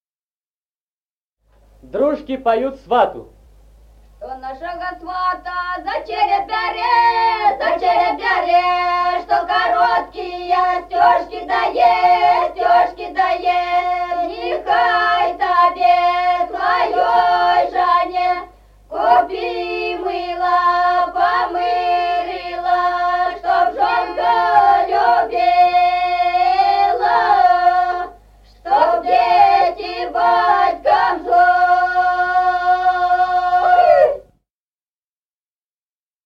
Музыкальный фольклор села Мишковка «Что нашего свата», свадебная.